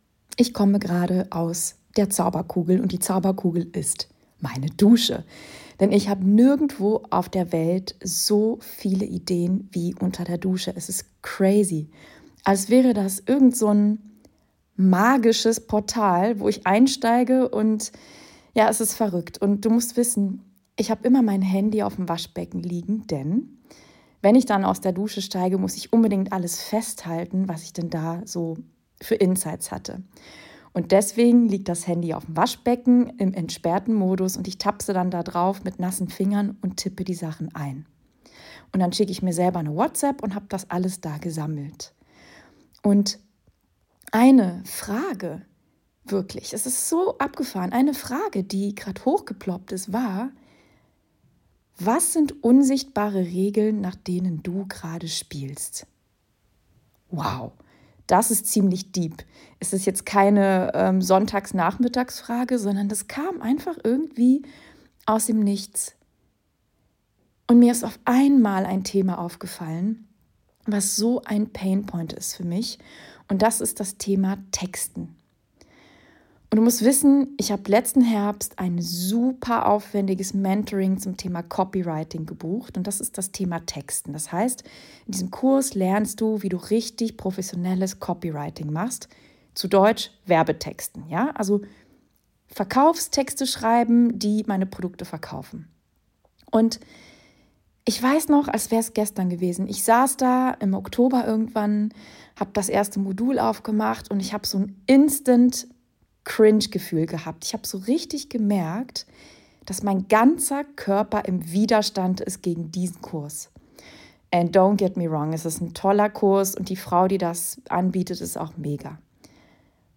#130 | Wie lebt es sich als Journalistin in Tel Aviv? Interview